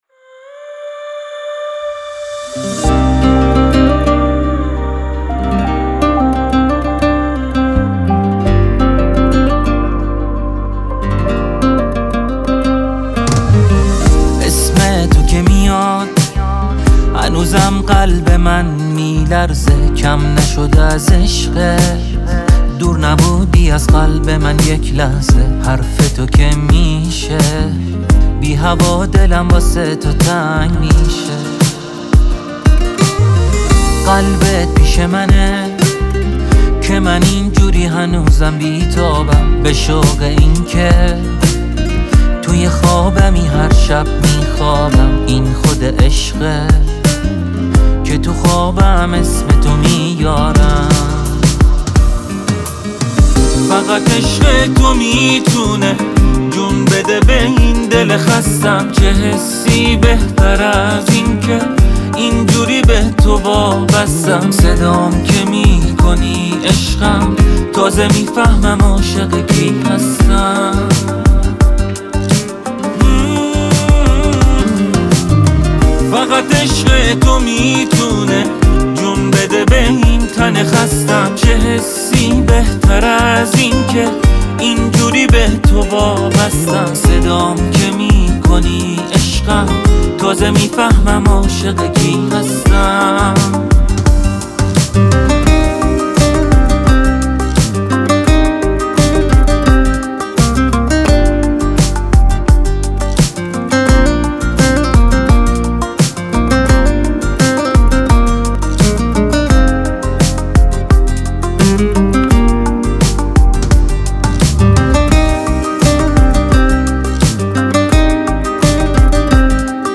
تک آهنگ جدید
خواننده پاپ